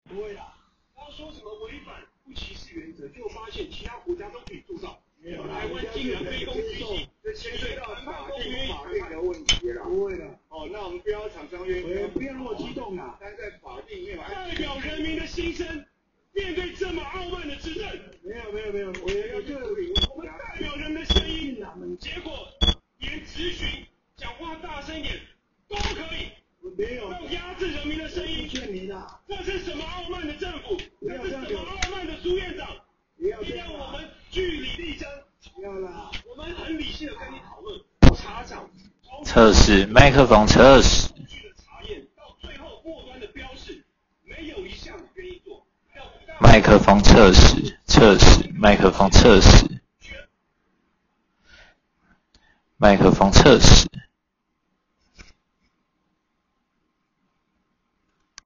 ● 一鍵快錄，內建2顆「直播式」全指向收音麥克風，搭配專業DSP降噪技術，可清晰收到2米內的聲音，內建記憶體可錄製9小時
有些政治內容的影片 😅😅😅 就給大家參考剛好如果拿 Adonit AI-Vocal 語音觸控筆來當錄音筆及轉逐字稿的話狀況如何！再提醒有翻譯成逐字稿的內容是我直接近距離對它講話內容才有辨識到的部分～大家就聽聽看錄音檔案當參考唄！